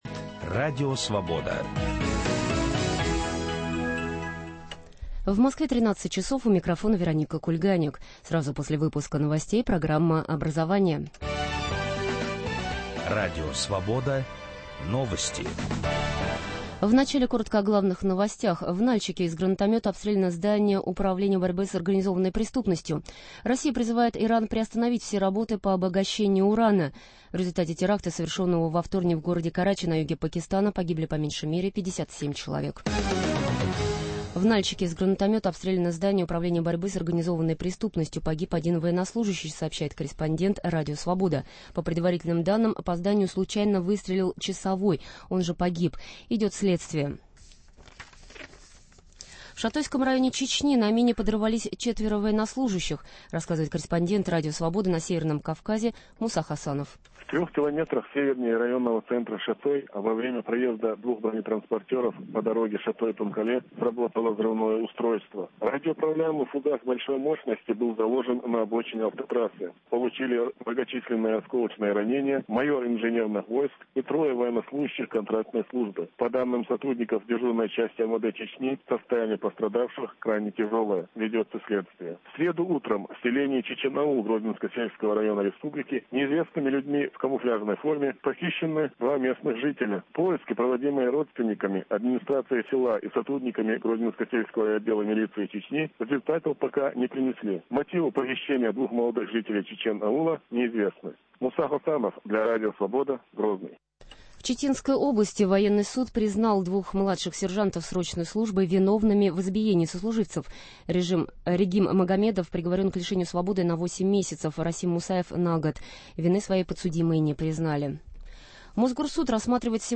Решения Государственного совета по проблемам образования. В студии: Заместитель председателя комитета государственной думы по образованию и науке Олег Смолин